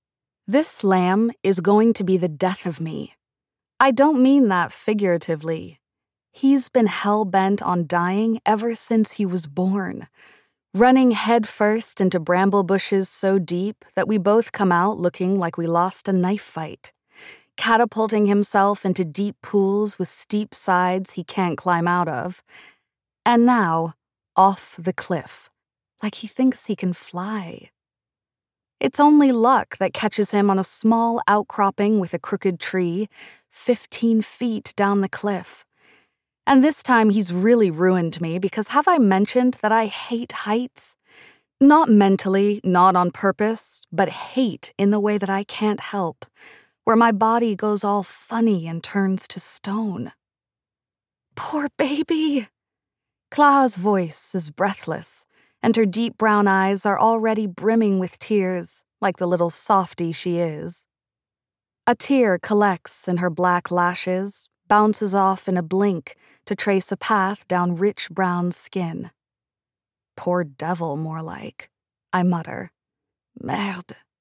It’s just one week until WE ARE THE BEASTS is out, which means it’s time for a little sneak peek at the first page of the audiobook.
We-Are-the-Beasts_audiobook-clip.mp3